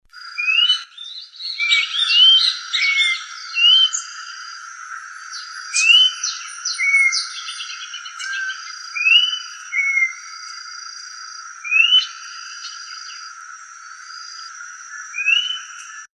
Orange-backed Troupial (Icterus croconotus)
Life Stage: Adult
Country: Argentina
Location or protected area: Reserva Ecológica Costanera Sur (RECS)
Condition: Wild
Certainty: Recorded vocal